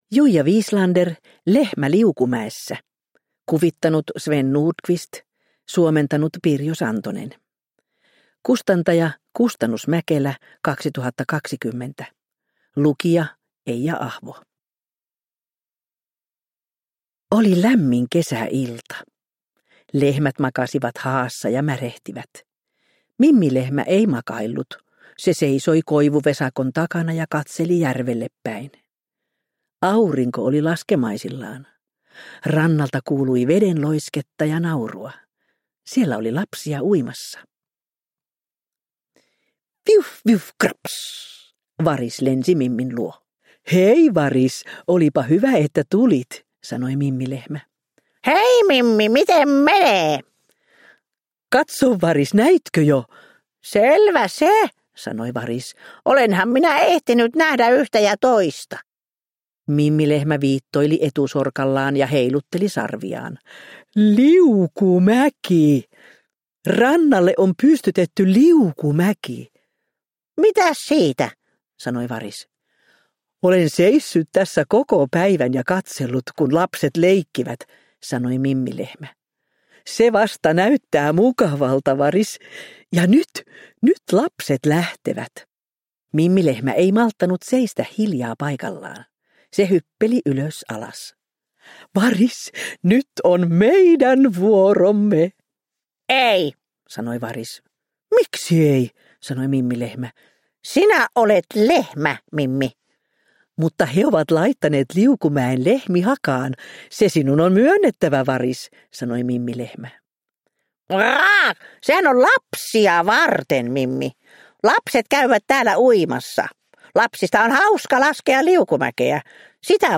Lehmä liukumäessä – Ljudbok – Laddas ner